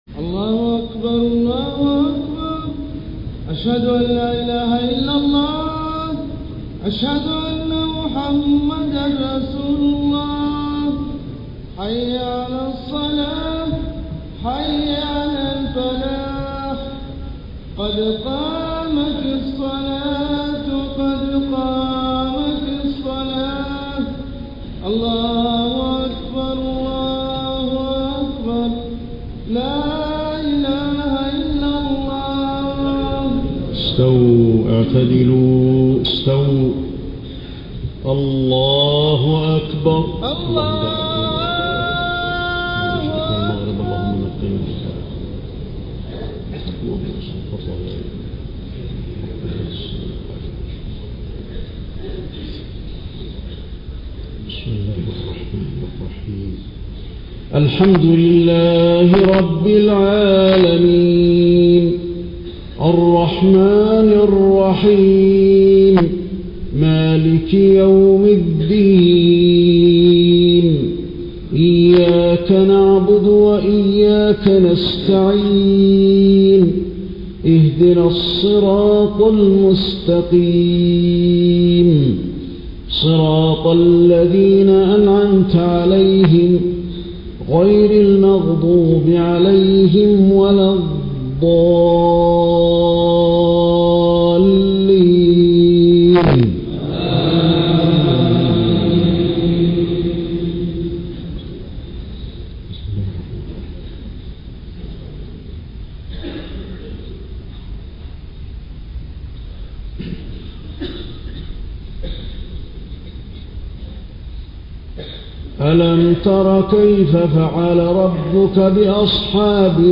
صلاة الجمعة 1 محرم 1431هـ سورتي الفيل و قريش > 1431 🕌 > الفروض - تلاوات الحرمين